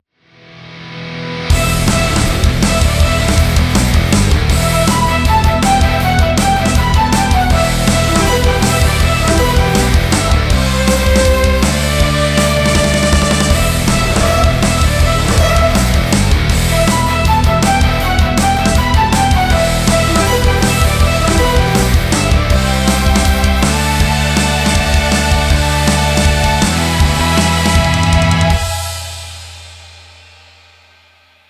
Fall is a good time for orchestral metal